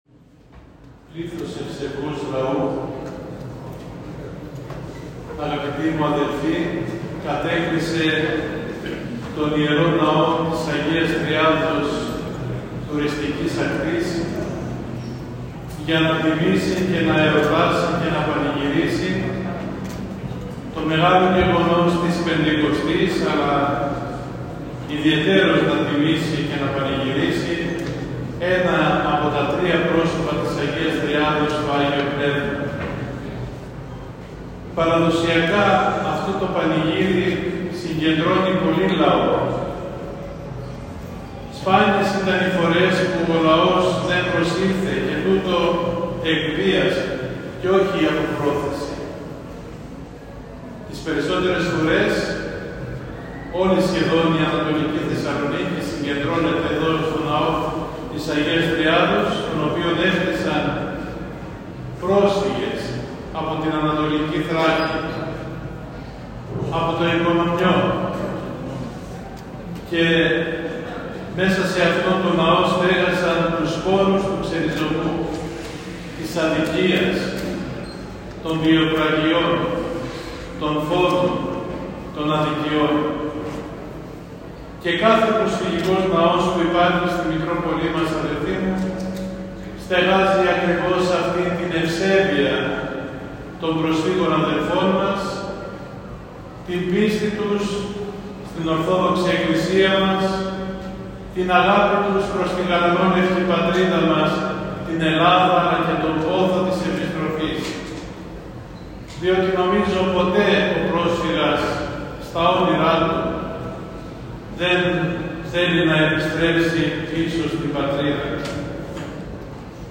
Στον πανηγυρικό Αρχιερατικό Εσπερινό χοροστάτησε και κήρυξε τον Θείο Λόγο ο Μητροπολίτης Νέας Κρήνης και Καλαμαριάς κ. Ιουστίνος τον οποίο μπορείτε να ακούσετε στο ακόλουθο ηχητικό αρχείο: